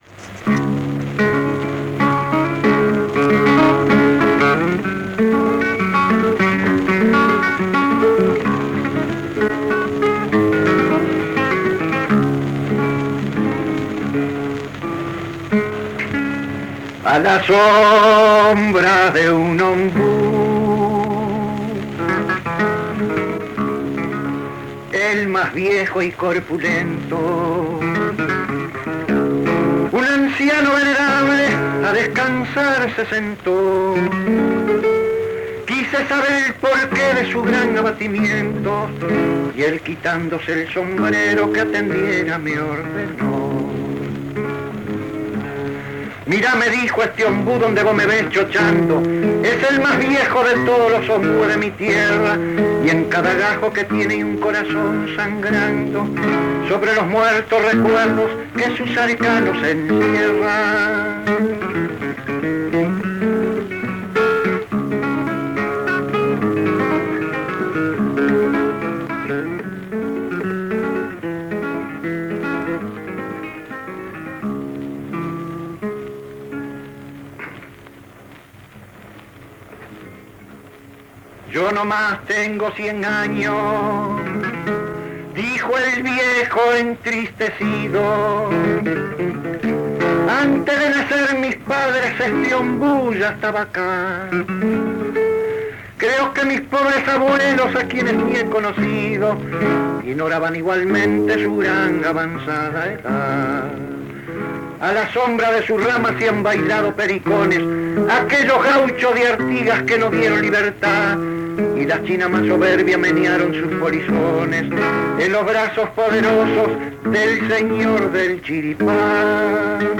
Especie: canción criolla
canto y guitarra
Formato original de la grabación: disco de acetato Audiodisc de 25 cm de base metálica a 78 rpm
Ayestarán anotó que el estado original de la grabación era «regular».